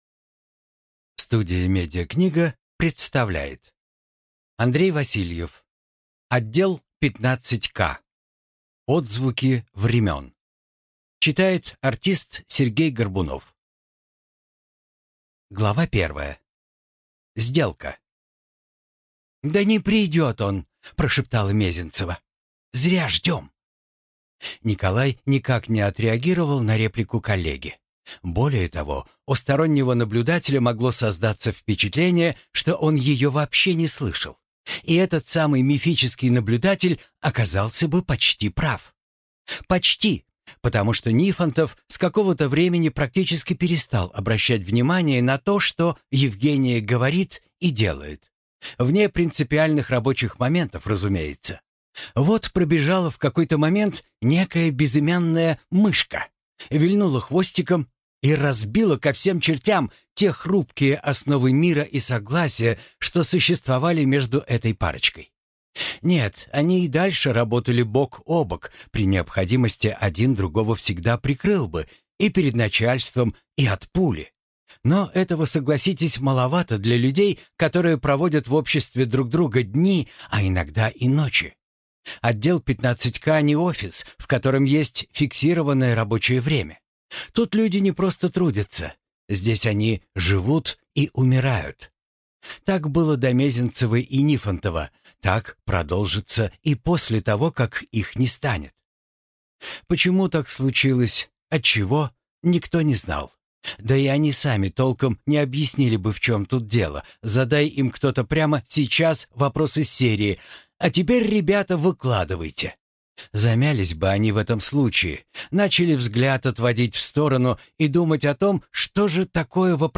Аудиокнига Отдел 15-К. Отзвуки времен | Библиотека аудиокниг